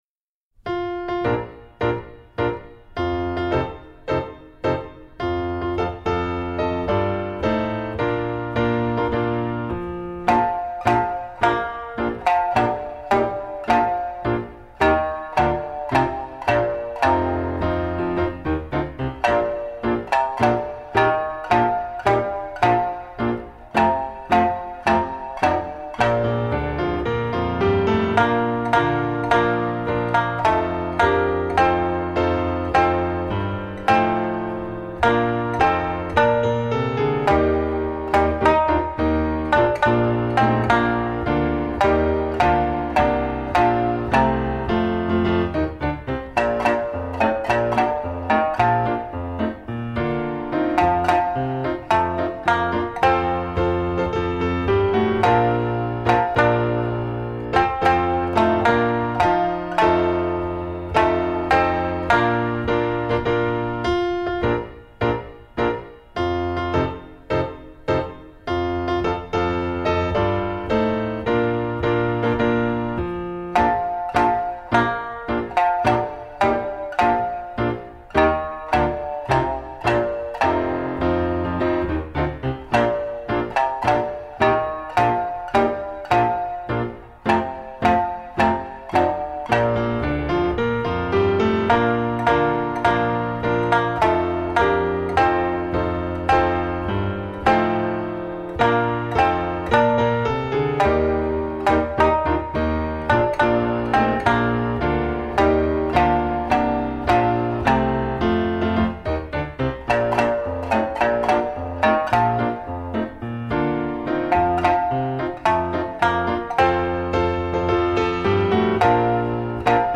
三線メロディーのみ